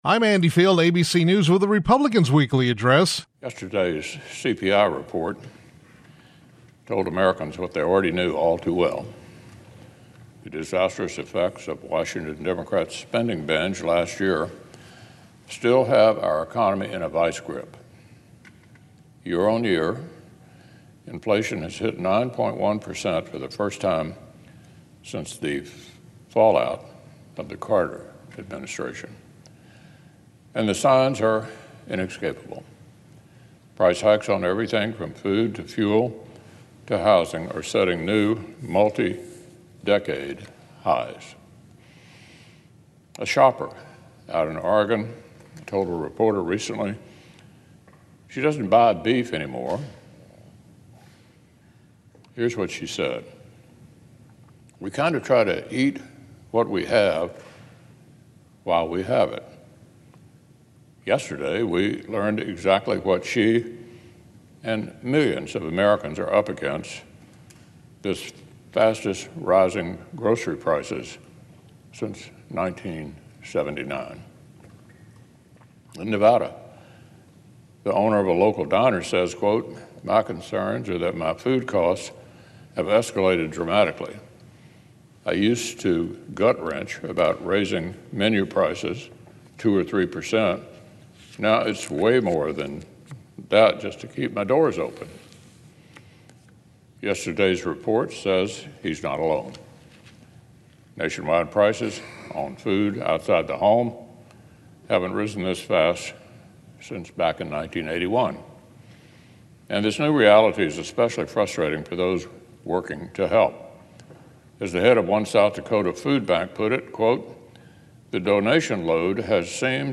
U.S. Senate Republican Leader Mitch McConnell (R-KY) delivered remarks on the Senate floor regarding the economy.